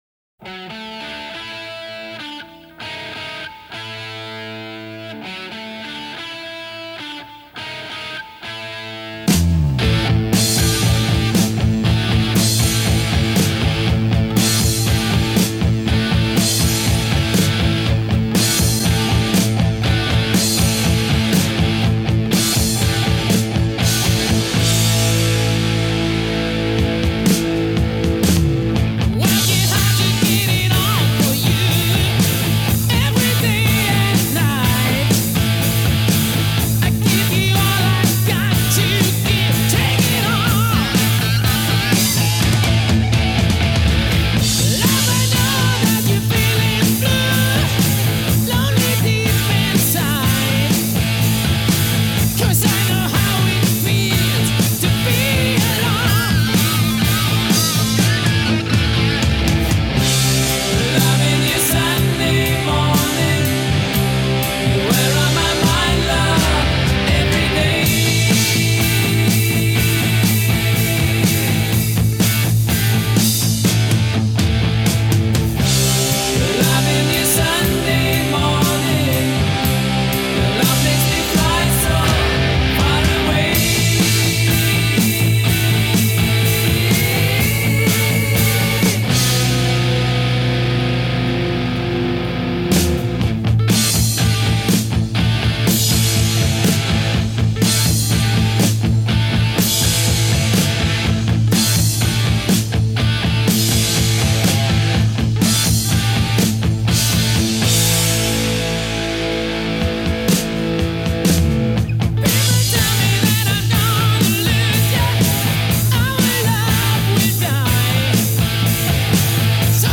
Posted by on November 10, 2013 in Rock and tagged , , .